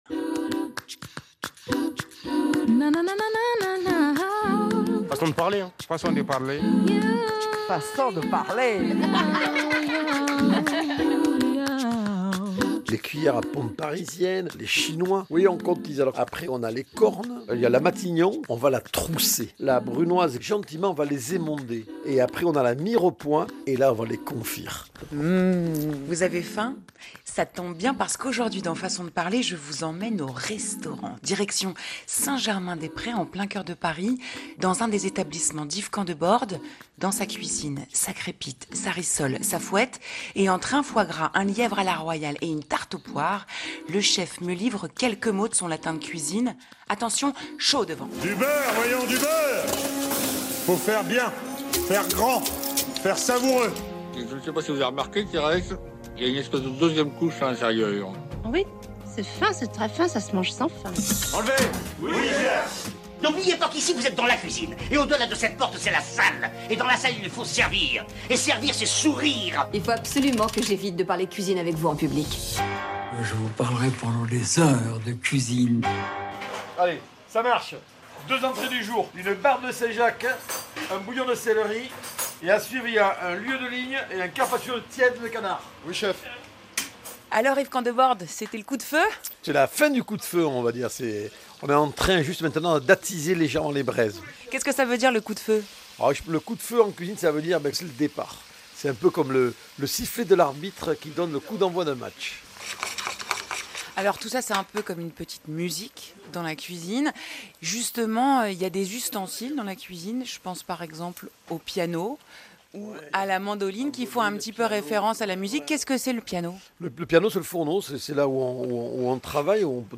Émission de radio